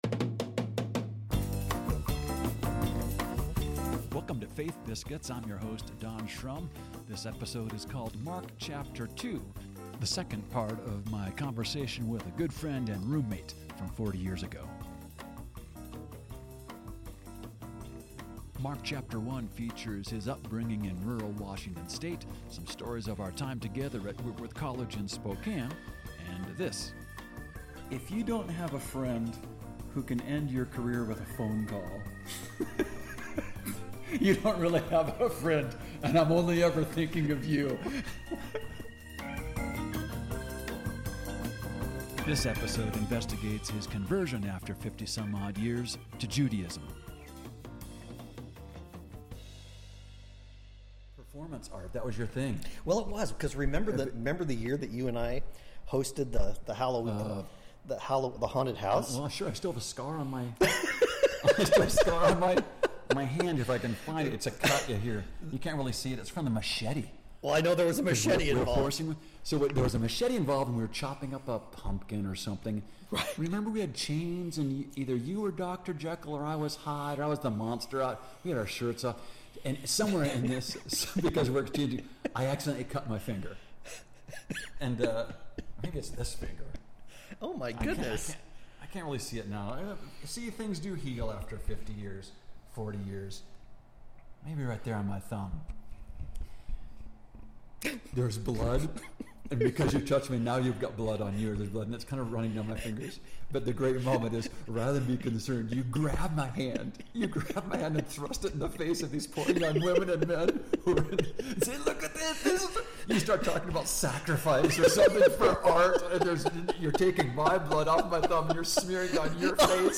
Part two of our conversation in Eugene OR about family and faith.